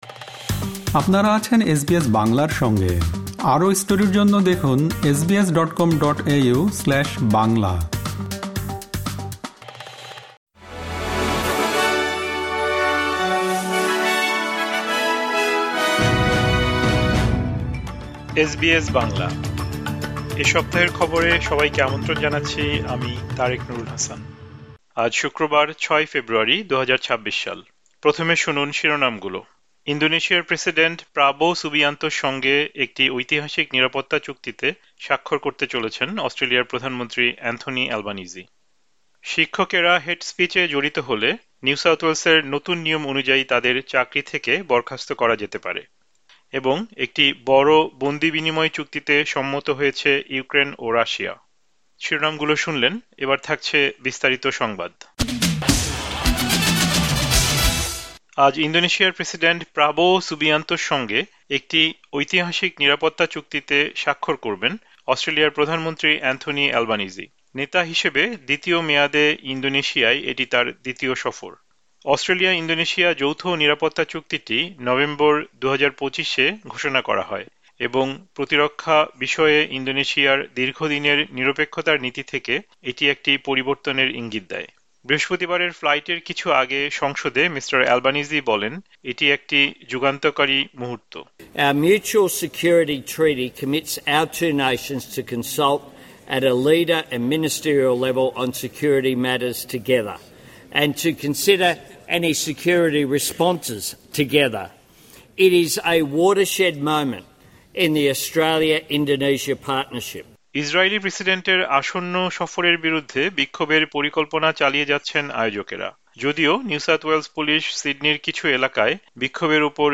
আজ শুক্রবার, ৬ ফেব্রুয়ারিতে প্রচারিত অস্ট্রেলিয়ার এ সপ্তাহের জাতীয় ও আন্তর্জাতিক গুরুত্বপূর্ণ সংবাদ শুনতে উপরের অডিও-প্লেয়ারটিতে ক্লিক করুন।